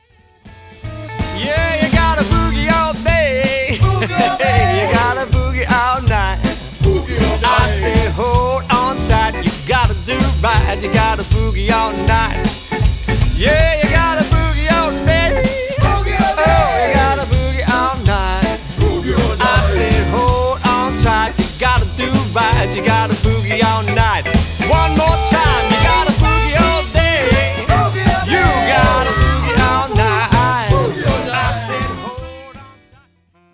• Classic swing jazz and jive band
• Five-piece instrumental line-up
• Guitar, bass, drums, trumpet, sax, vocals